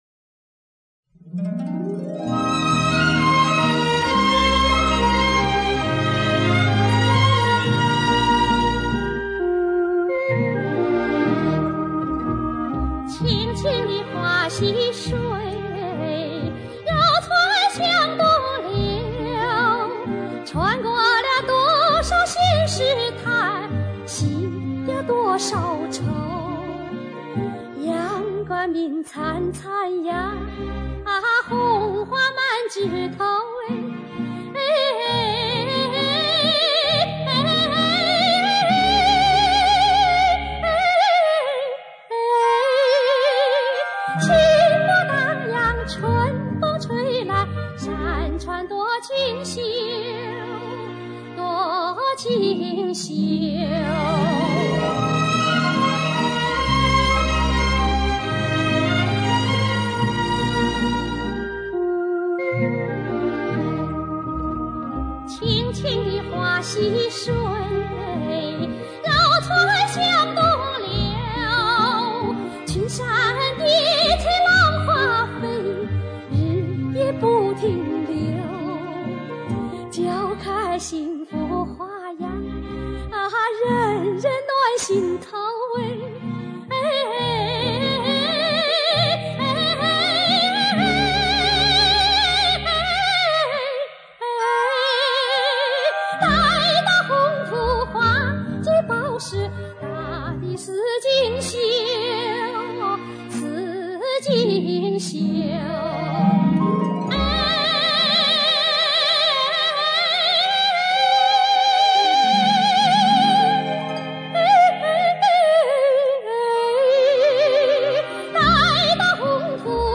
是大陆七、八十年代的流行金曲